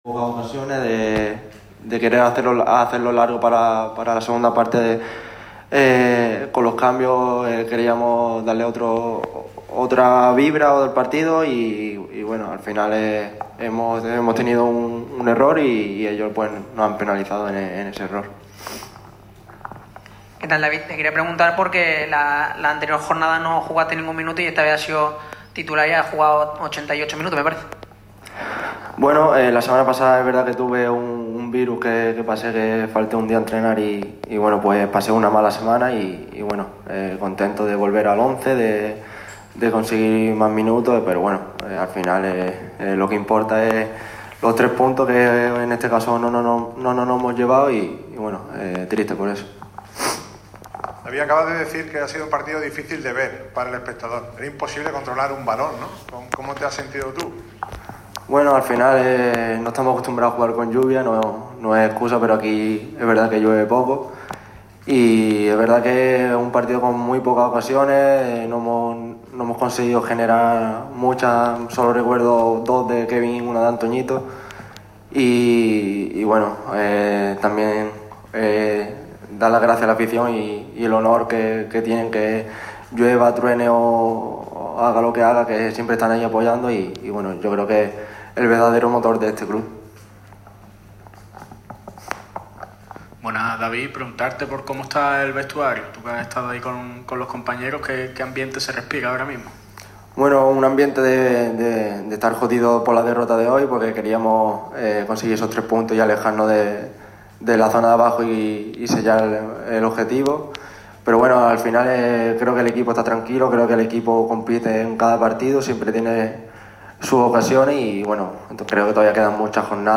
El extremo malaguista ha comparecido ante los medios tras la derrota de los boquerones a manos del Cádiz CF (0-2) en el partido relativo a la jornada 30 en La Rosaleda.